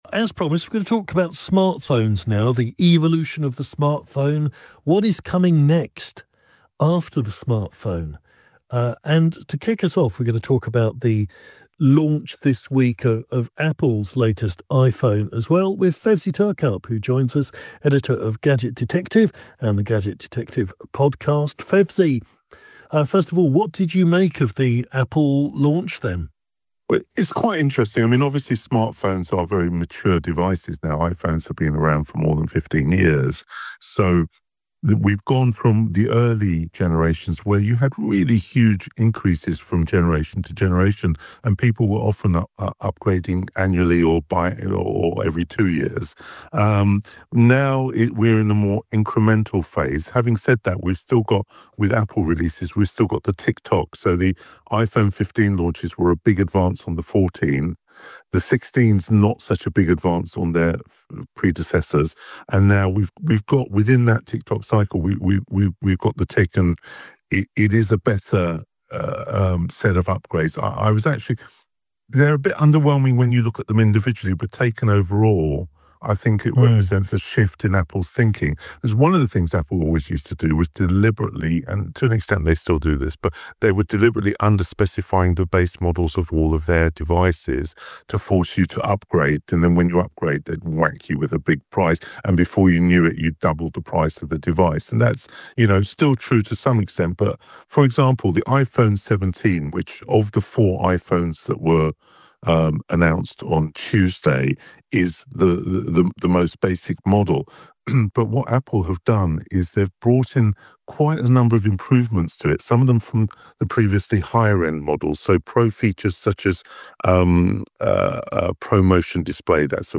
12th September 2025 - Discussing the new Apple iPhone & Murena & FairPhone 6 launches on LBC Radio